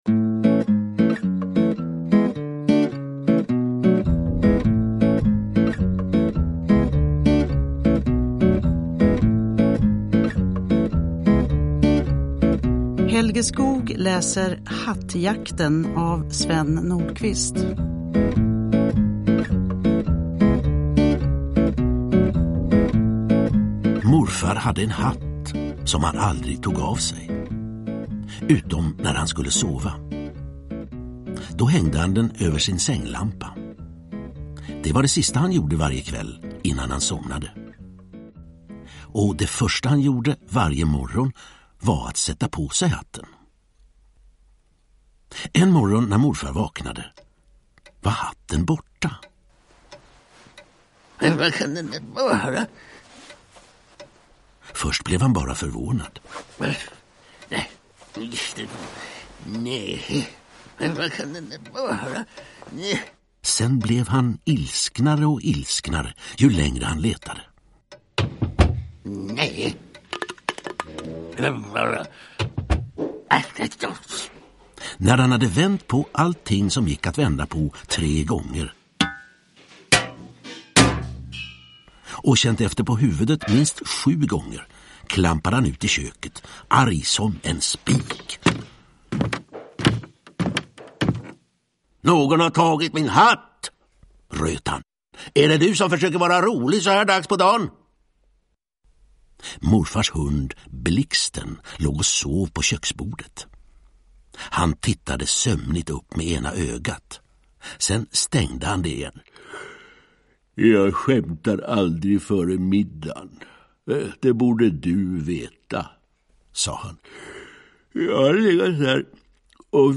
Hattjakten – Ljudbok – Laddas ner
Uppläsare: Helge Skoog